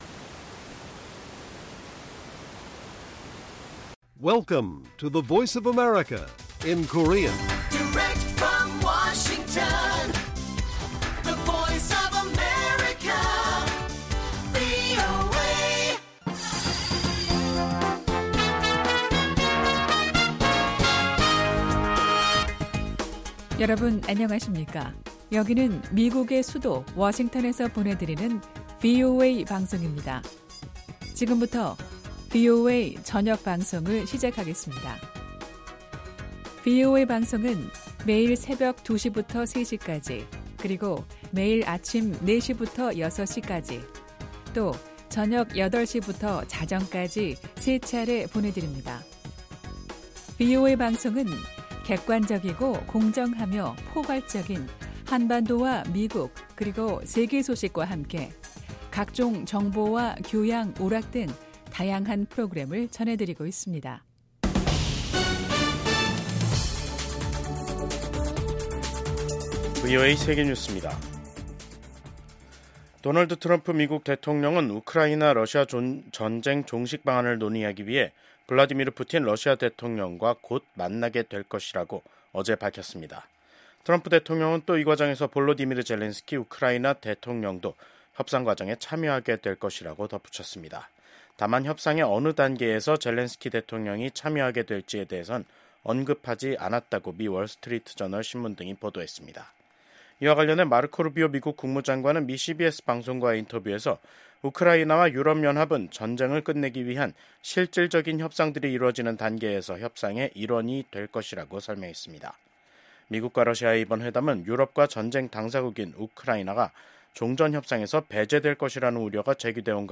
VOA 한국어 간판 뉴스 프로그램 '뉴스 투데이', 2025년 2월 17일 1부 방송입니다. 미국과 한국, 일본의 외교장관들이 북한의 완전한 비핵화에 대한 확고한 의지를 재확인했습니다. 15일 열린 미한일 외교장관 회의에서 북한의 완전한 비핵화 목표를 확인한 데 대해 도널드 트럼프 행정부가 투트랙 대북전략을 구사할 것이라는 관측이 나왔습니다.